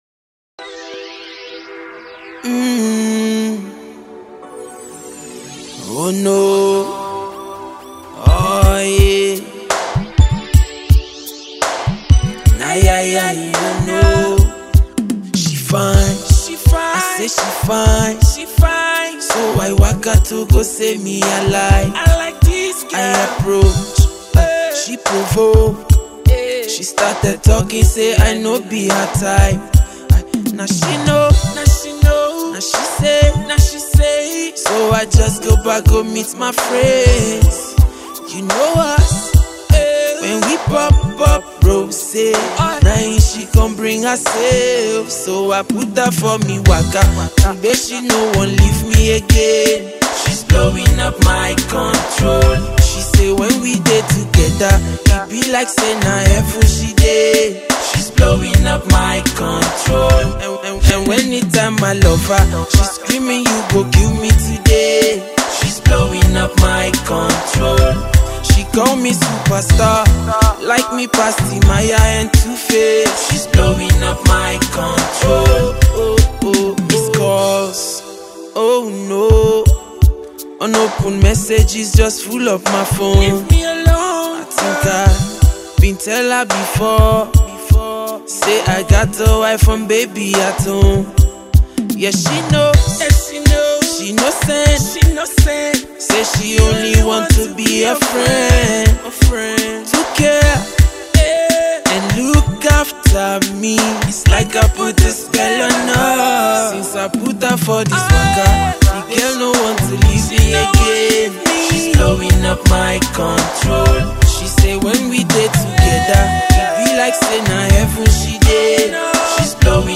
toned down his familiar hard edge
is a laid-back tune